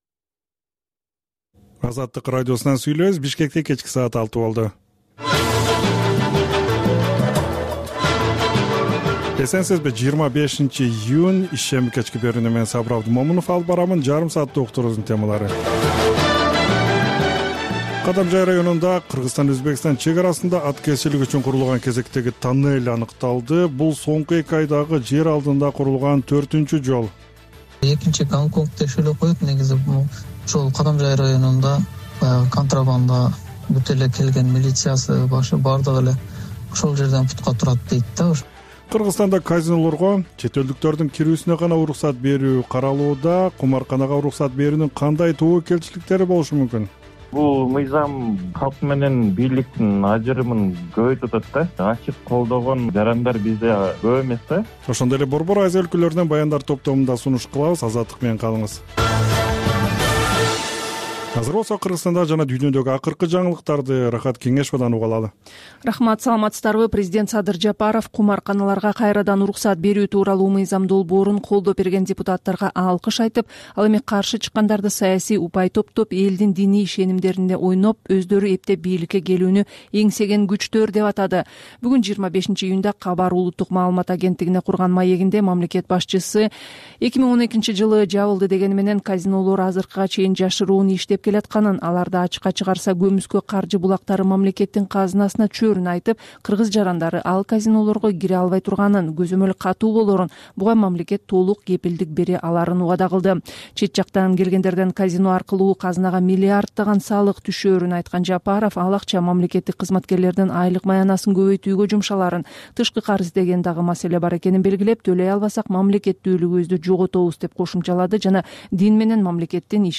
Бул үналгы берүү ар күнү Бишкек убакыты боюнча саат 18:00ден 18:30га чейин обого түз чыгат.